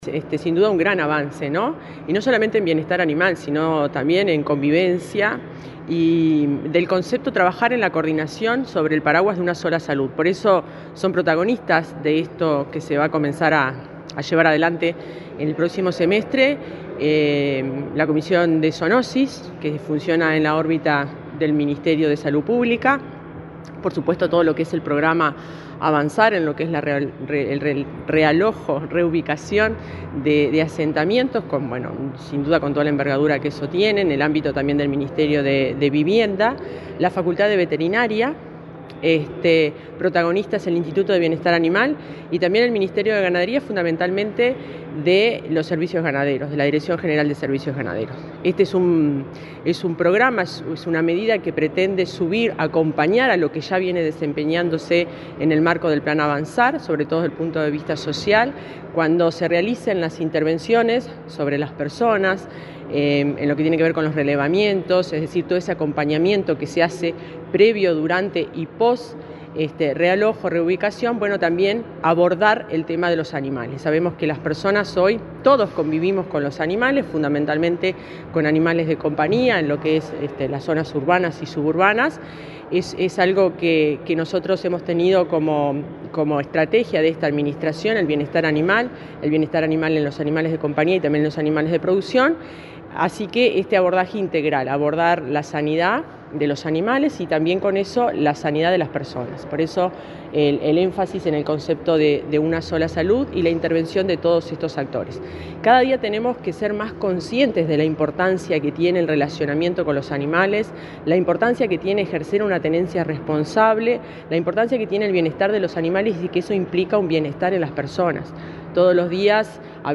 Entrevista a la directora general del MGAP, Fernanda Maldonado
La directora general del Ministerio de Ganadería, Agricultura y Pesca (MGAP), Fernanda Maldonado, dialogó con Comunicación Presidencial, este lunes 15 en la Torre Ejecutiva, acerca de la firma de un acuerdo con el Ministerio de Vivienda, Zoonosis y la Facultad de Veterinaria, para la atención de animales de compañía y de producción.